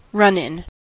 run-in: Wikimedia Commons US English Pronunciations
En-us-run-in.WAV